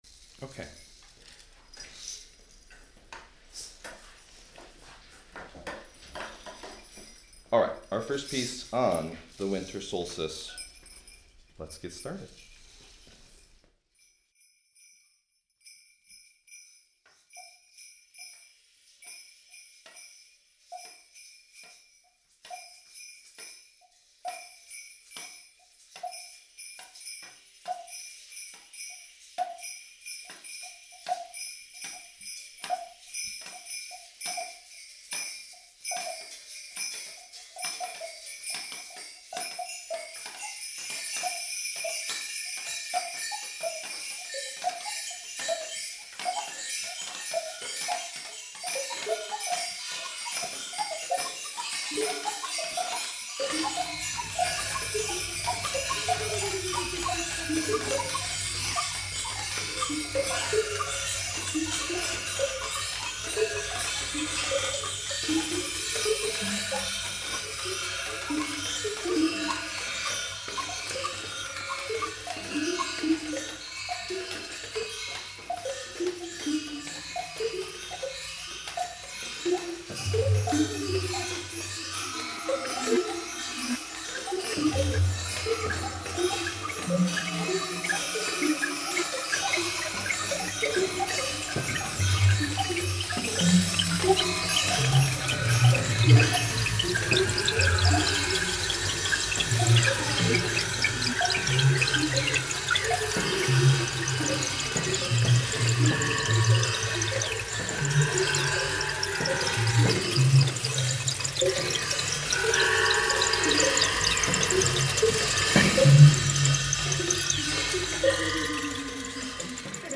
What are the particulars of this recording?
presented an accessible youth and family art workshop recorded an improvised performance using the instruments in the installation. ambisonic microphone